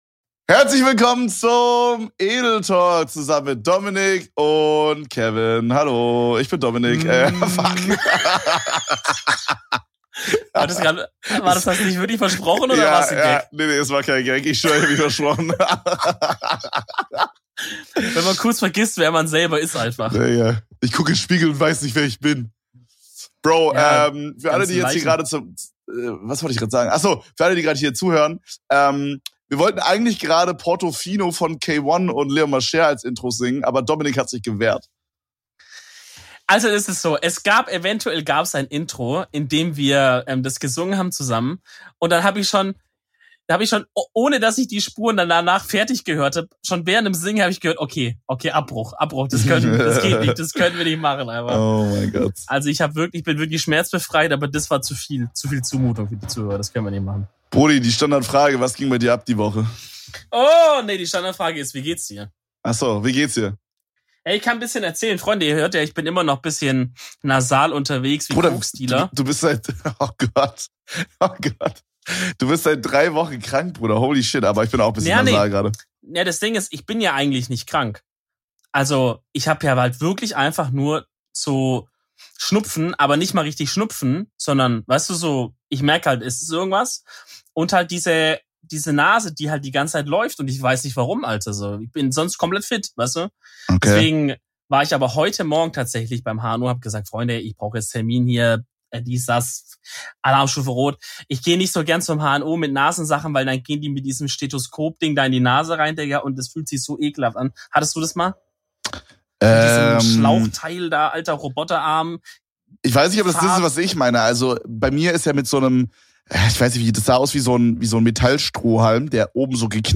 In dieser Episode erwartet euch ein sehr nasales Hörvergnügen